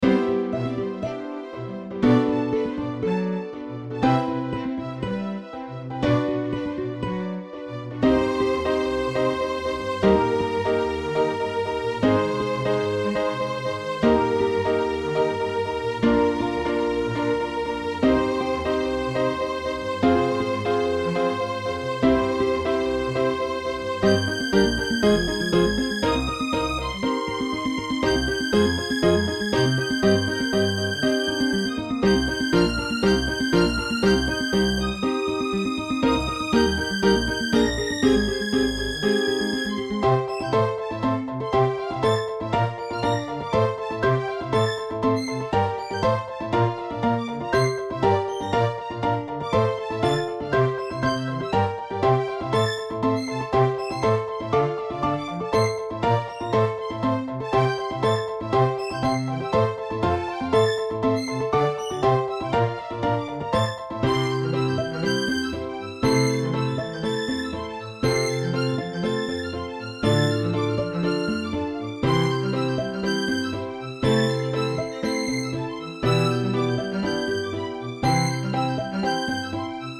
ストリング アンサンブル2、オーケストラル ハープ、コントラバス、ピアノ
種類BGM